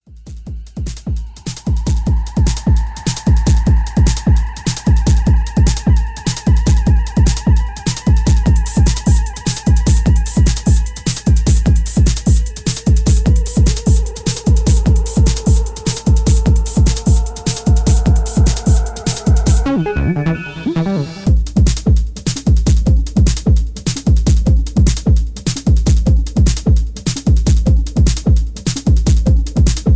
Tags: dark, ominous